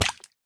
Minecraft Version Minecraft Version snapshot Latest Release | Latest Snapshot snapshot / assets / minecraft / sounds / entity / fish / hurt3.ogg Compare With Compare With Latest Release | Latest Snapshot
hurt3.ogg